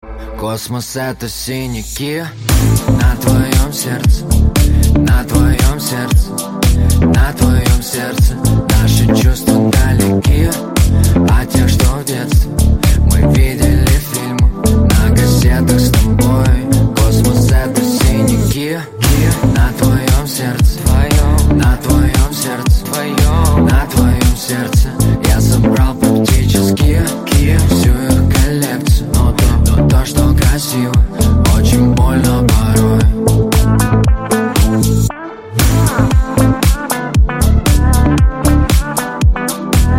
поп , романтические
мужской голос